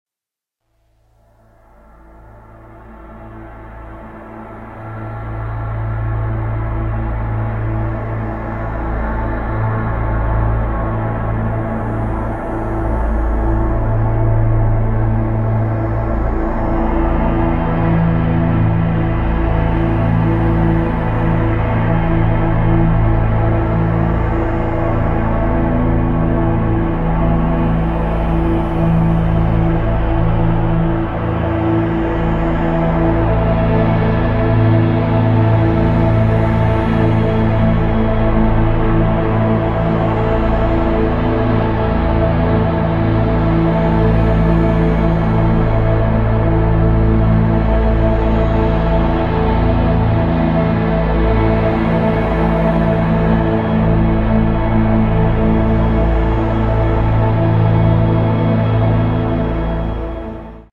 Cosmic Meditation | Deep Ambient sound effects free download
Deep Ambient Soundscape